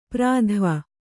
♪ prādhva